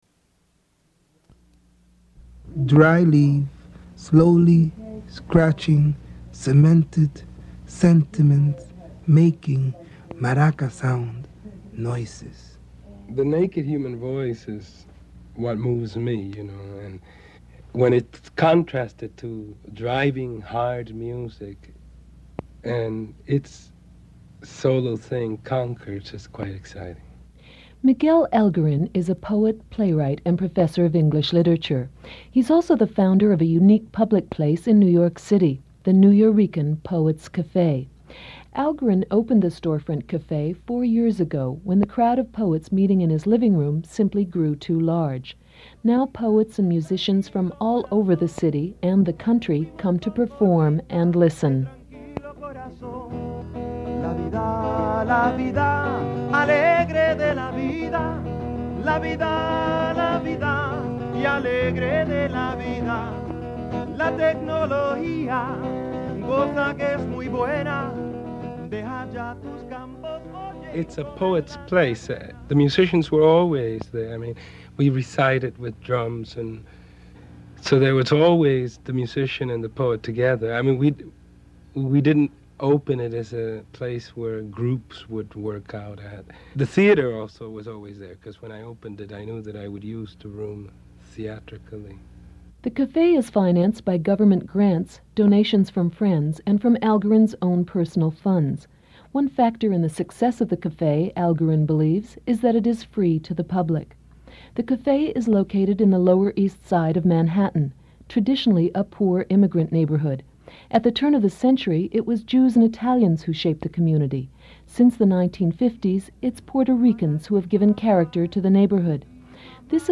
Nuyorican Poets Cafe, Lower East Side, New York